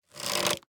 Minecraft Version Minecraft Version snapshot Latest Release | Latest Snapshot snapshot / assets / minecraft / sounds / item / crossbow / quick_charge / quick3_2.ogg Compare With Compare With Latest Release | Latest Snapshot